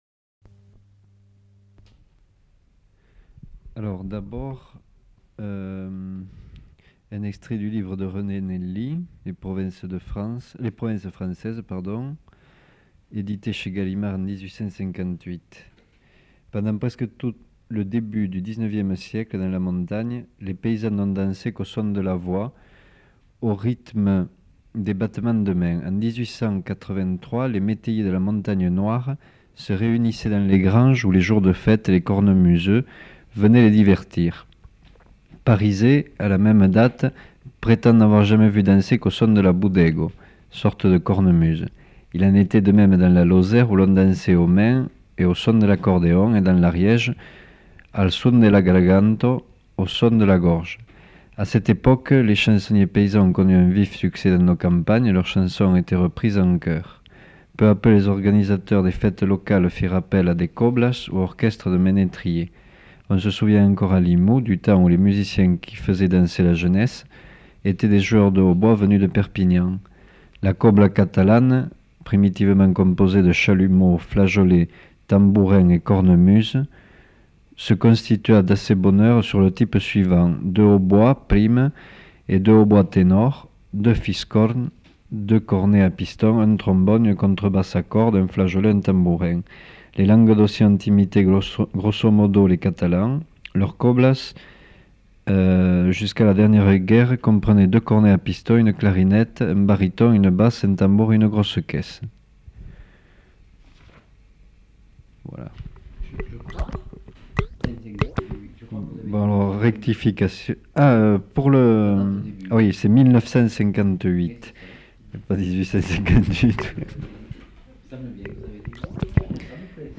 Lecture de textes sur la musique et les instruments de musique en Gascogne, Languedoc et Catalogne
Lieu : Toulouse
Genre : parole